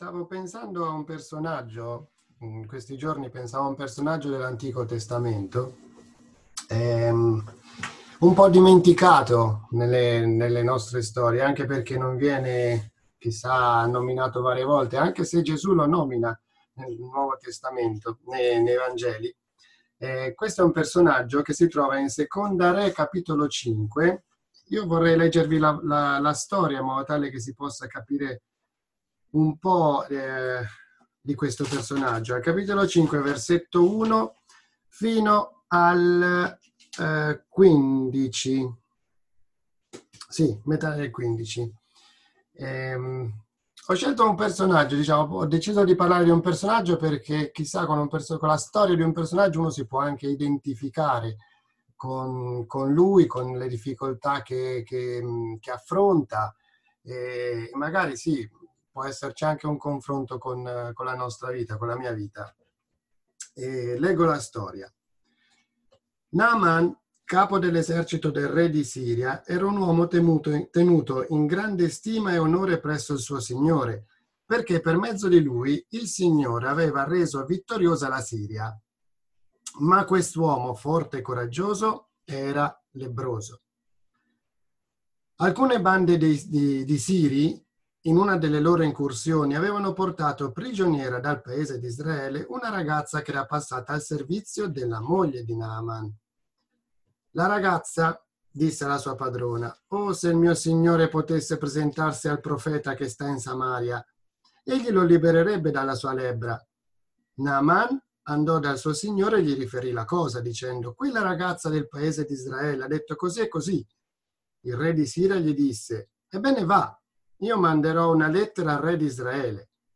2 Re 5:1-15 Tipo Di Incontro: Domenica Naaman